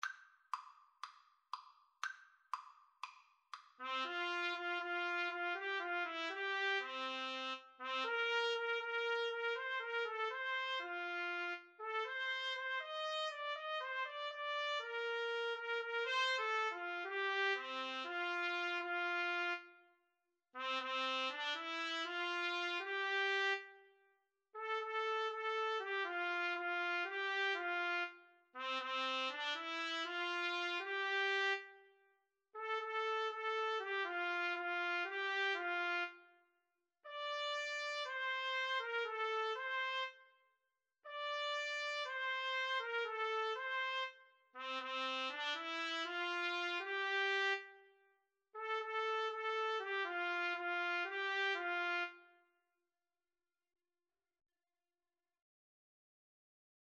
~ = 120 Fast, calypso style